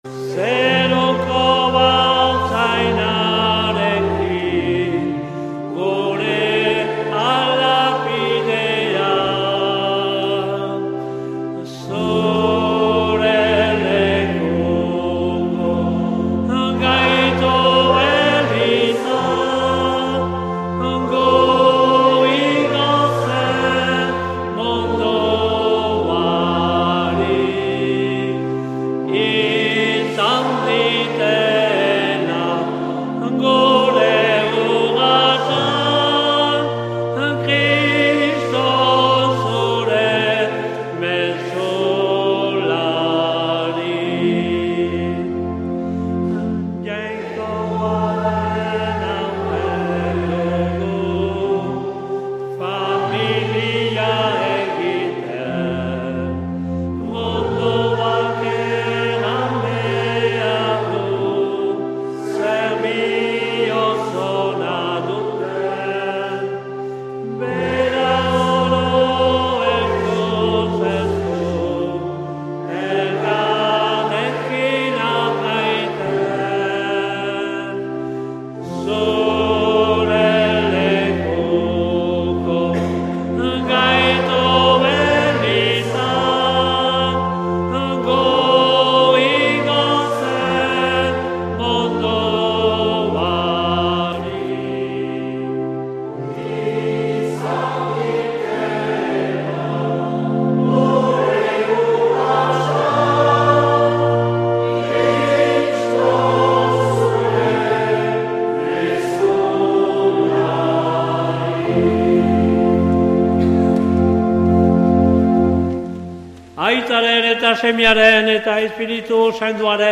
2026-01-18 Urteko 2. Igandea A - Baigorri